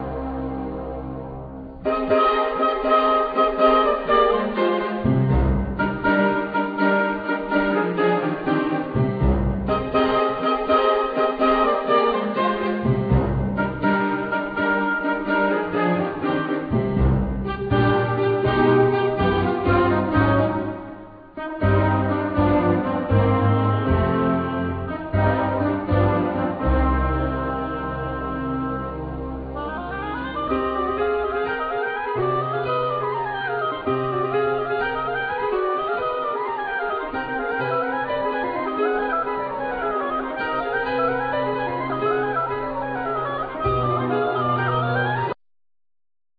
Classical&12 string guitar,Piano,synths
Oboe,Soprano sax,Bass Clarinet
Acoustic bass
Drums,Percussion